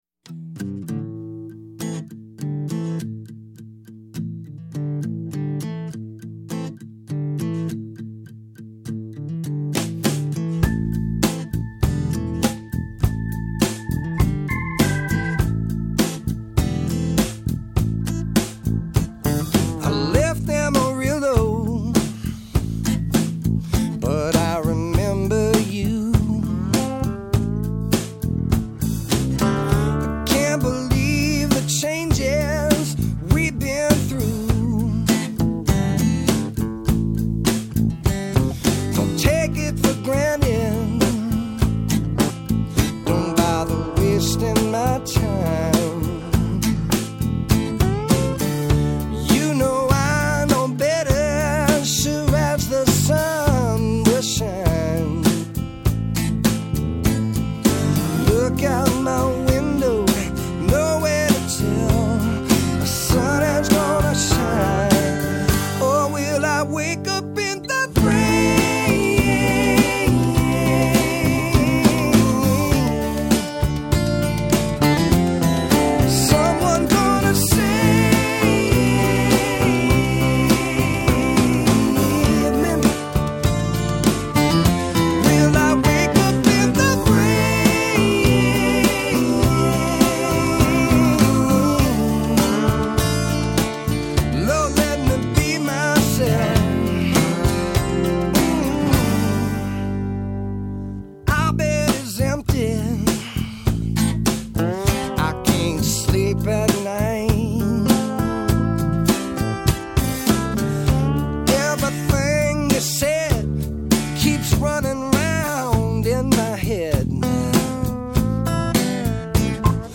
Жанр: country